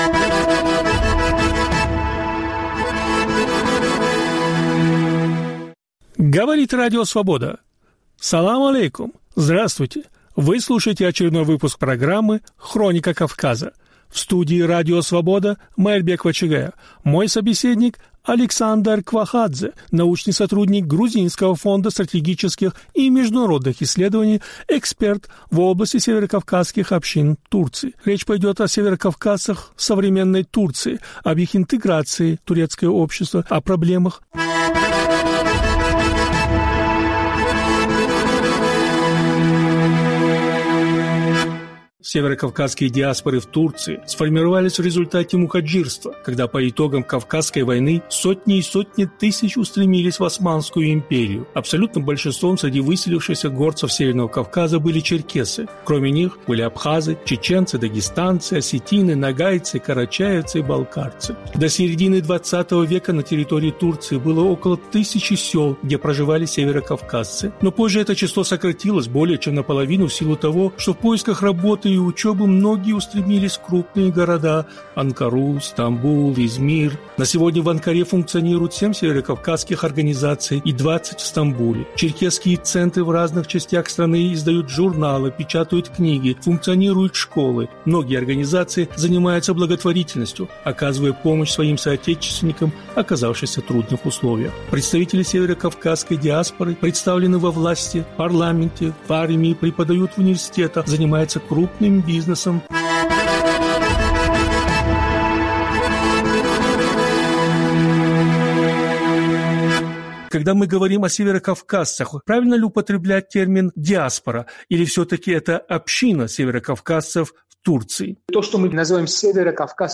Гость очередного выпуска радиопрограммы и подкаста